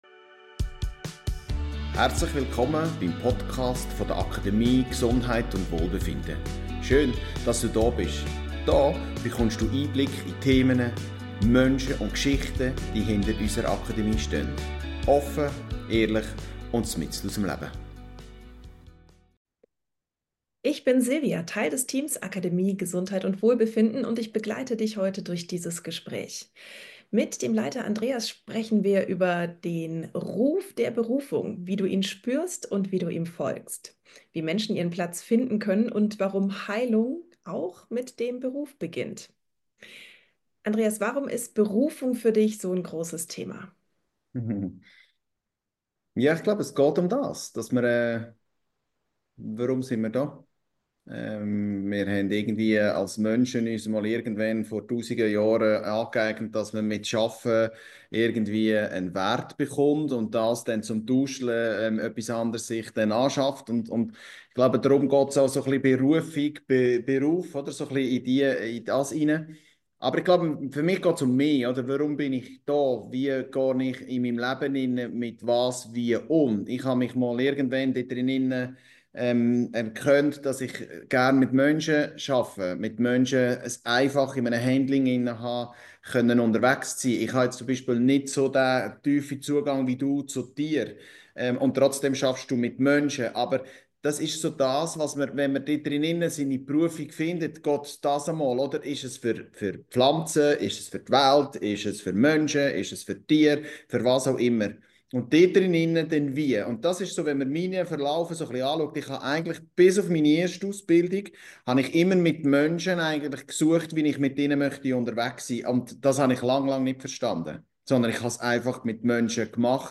Ein Gespräch über den Unterschied zwischen Beruf, Job und Berufung, über Selbstheilung als Teil des beruflichen Weges – und darüber, warum Schmerz, Sehnsucht und Sinn manchmal zusammengehören.